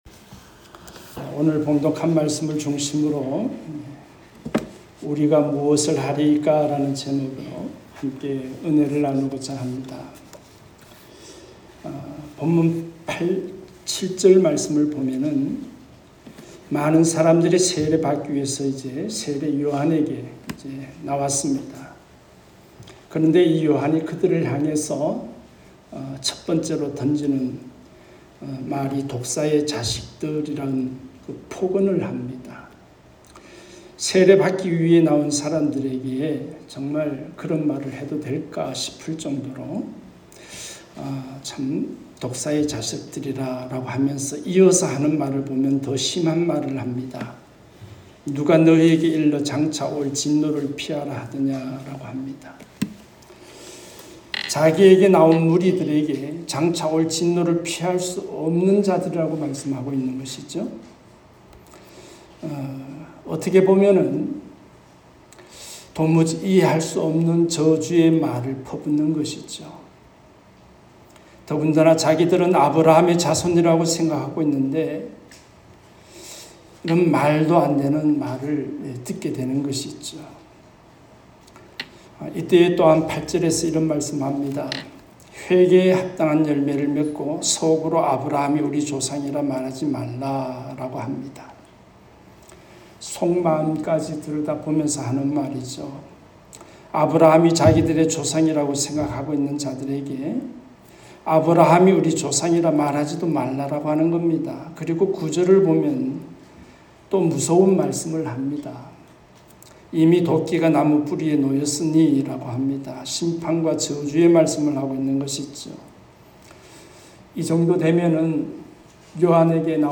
우리가 무엇을 하리이까? ( 눅3:7-14 ) 말씀